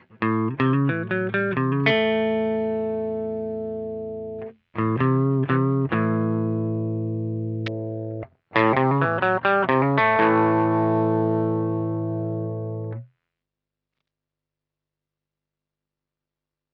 Ensuite tu ecoutes les 5 dernieres secondes, ou j'ai coupé la gratte, et tu ecoutes le souffle ... C'est le souffle d'une 2e tranche de console, avec le potard de volume et le PREAMPLI AU MAX !!!
souffle UB1204pro.wav